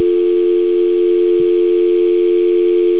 dial tone or
dial.au